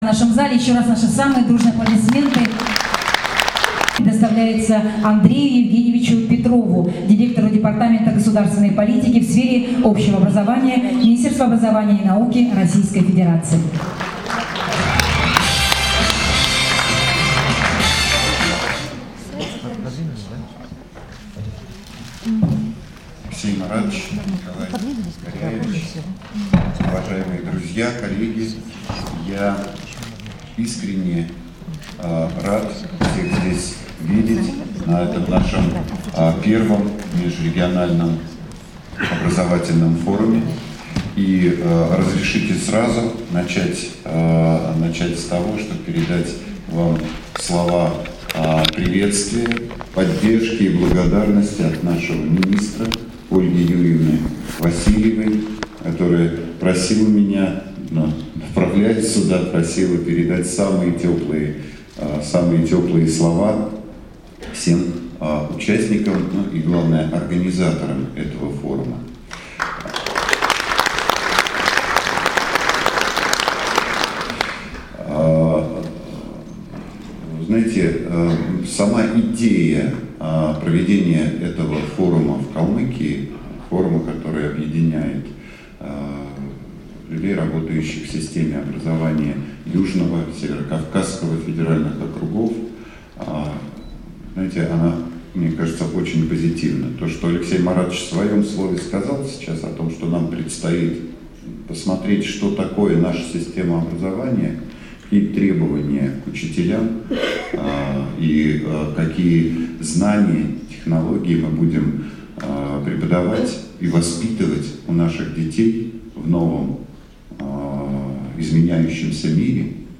Выступление директора Департамента государственной политики в сфере общего образования Минобрнауки России А.Е. Петрова